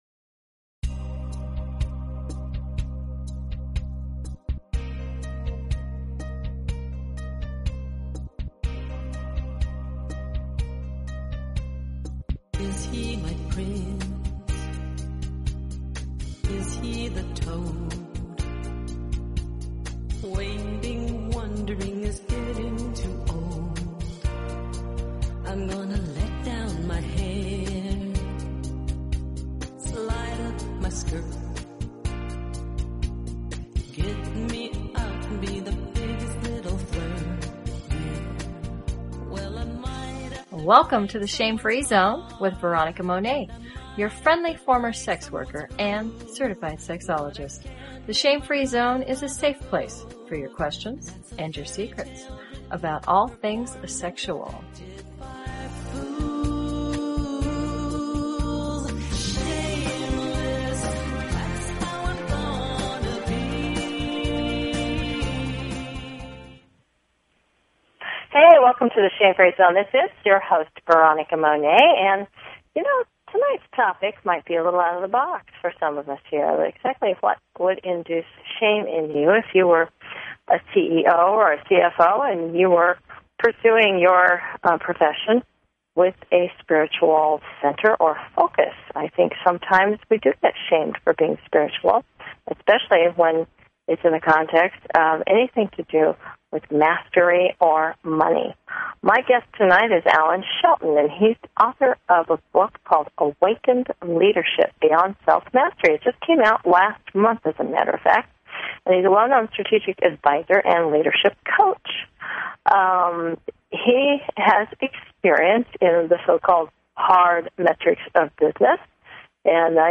Talk Show Episode, Audio Podcast, The_Shame_Free_Zone and Courtesy of BBS Radio on , show guests , about , categorized as